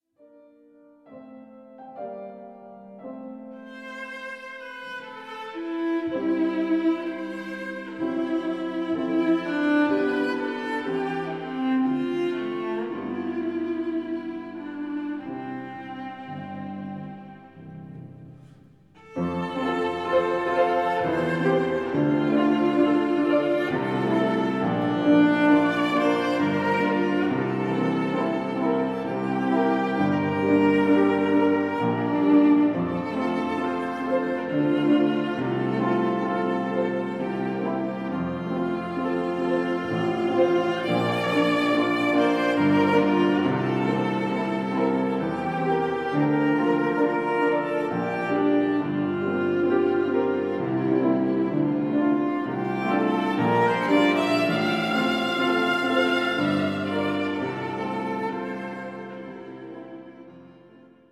(in der Trauerhalle)
klassische Musik